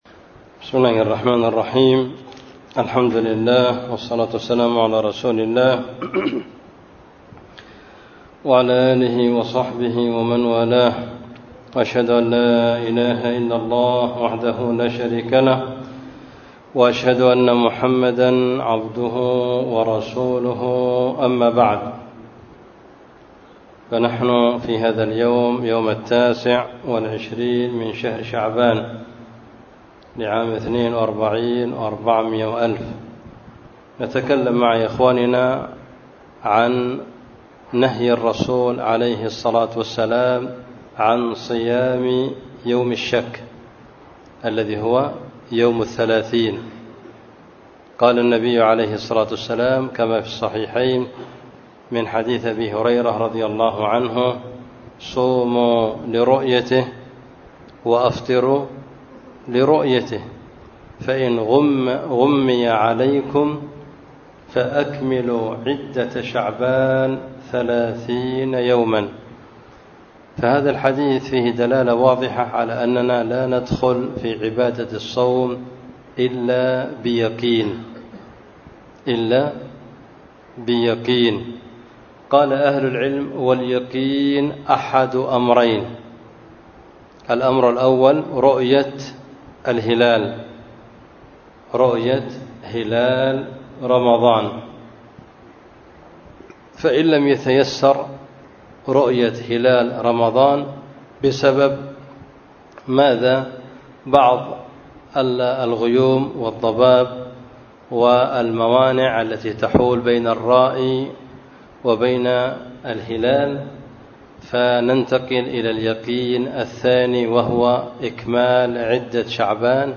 بدار الحديث في مسجد النصيحة بالحديدة